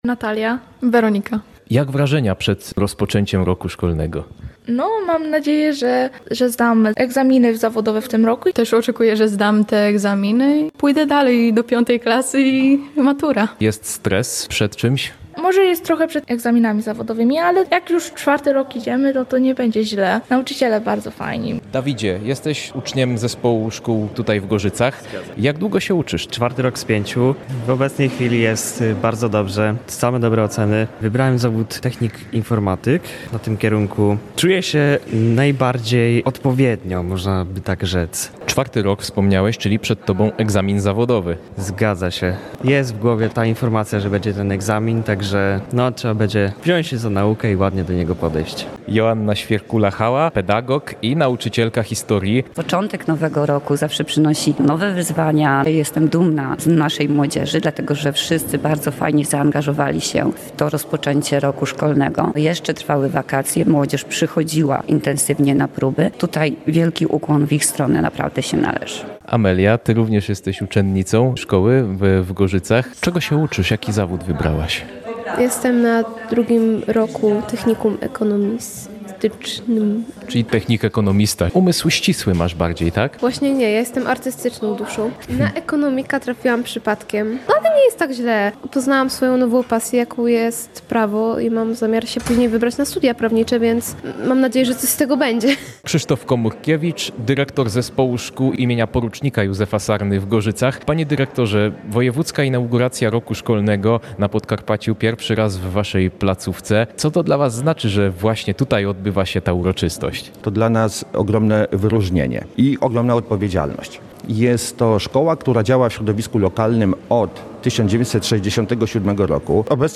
Otworzyło ją wprowadzenie uczniowskich pocztów sztandarowych oraz wygłoszenie okolicznościowych przemówień. Paweł Bartoszek, starosta tarnobrzeski, zwrócił uwagę, że gorzycka placówka kształci na bardzo wysokim poziomie.
Wojewodzkie-rozpoczecie-roku-szkolnego-w-Gorzycach.mp3